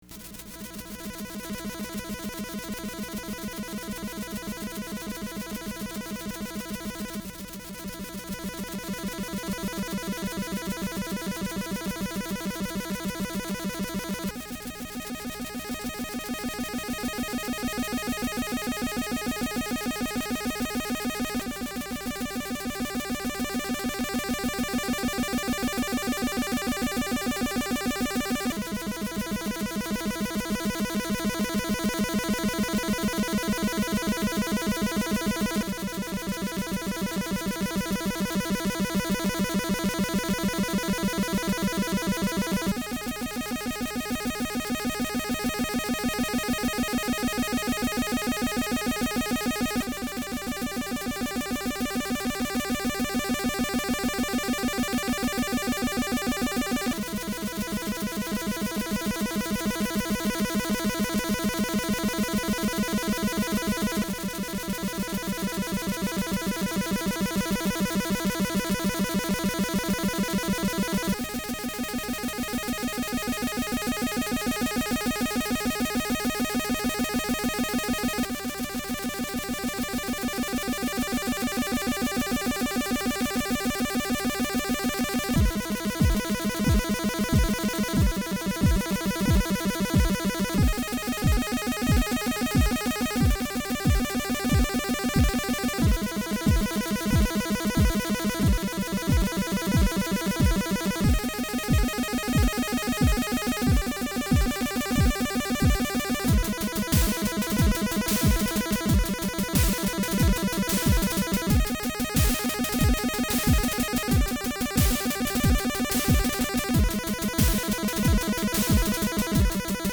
Genre: Chippo